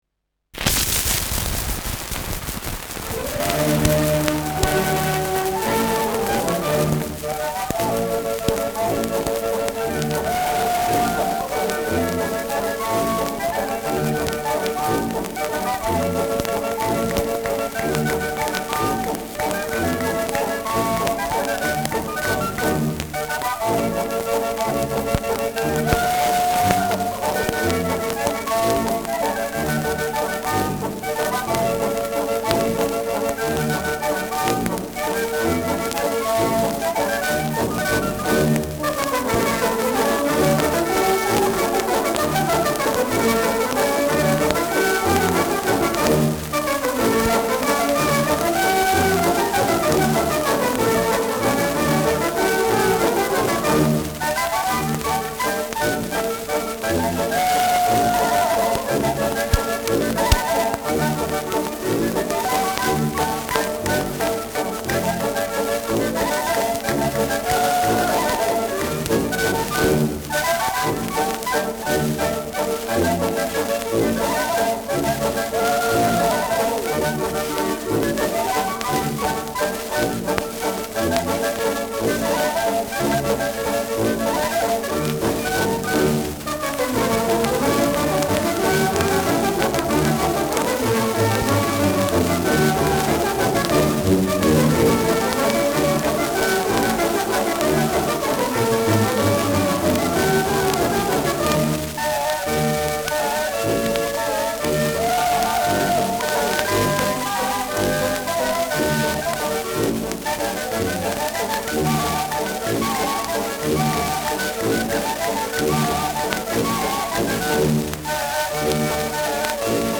Schellackplatte
Mit Juchzern und Klopfgeräuschen.
[Berlin] (Aufnahmeort)